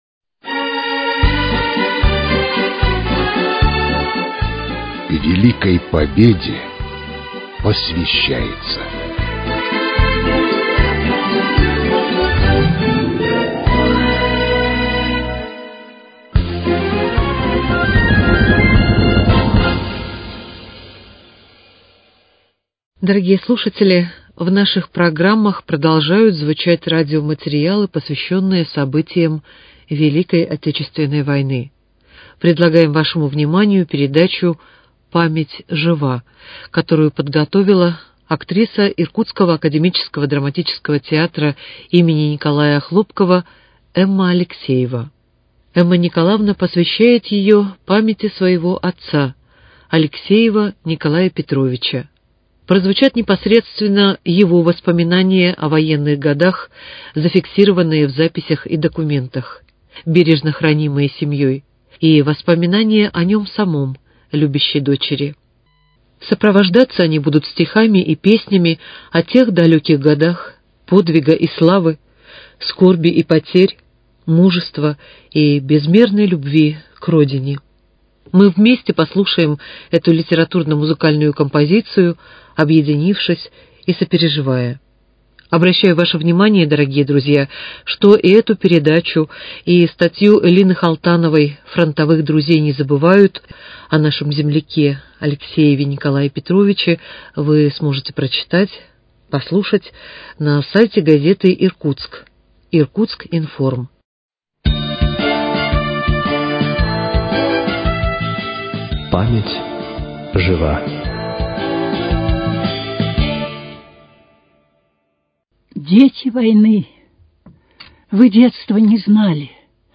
читает актриса